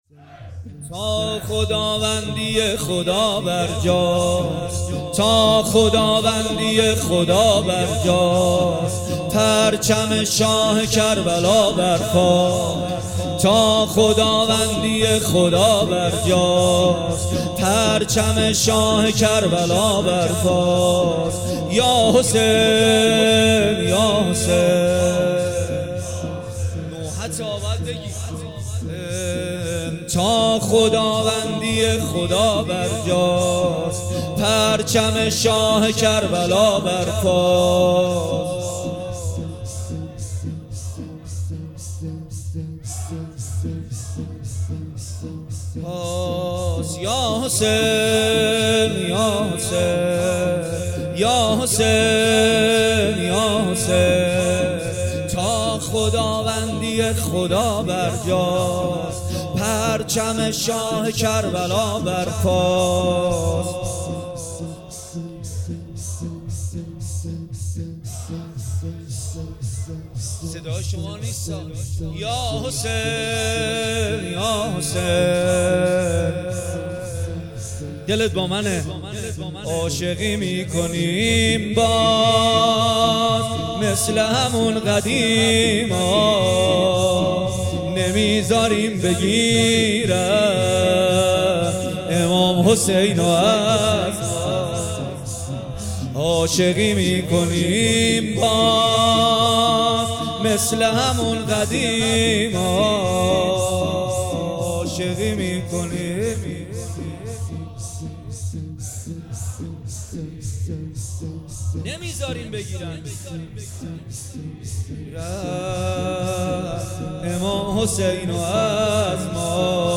مداحی جدید کربلایی محمدحسین پویانفر شب اول محرم 1399هیات ریحانة النبی(س) تهران